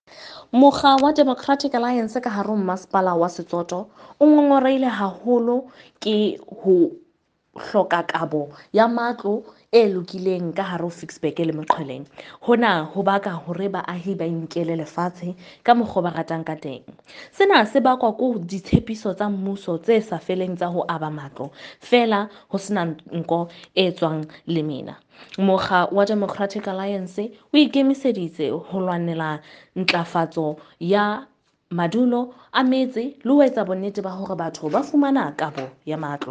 Sesotho by Karabo Khakhau MP.